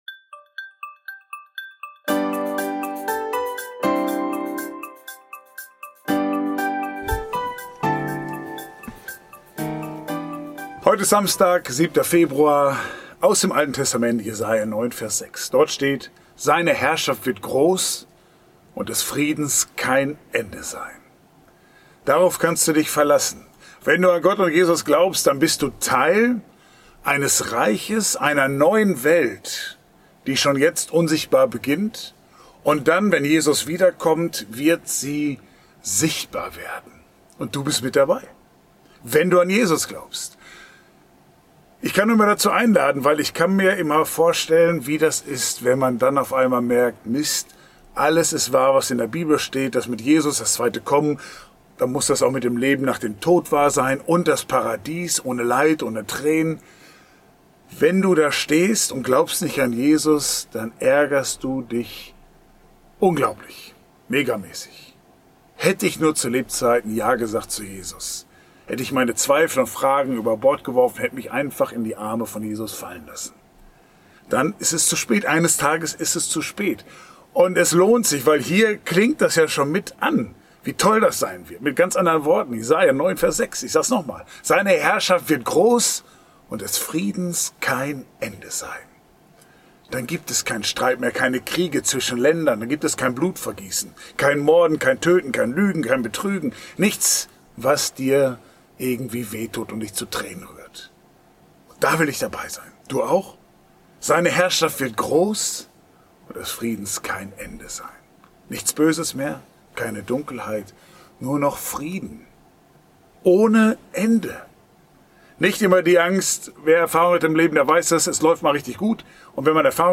Ein täglicher Impuls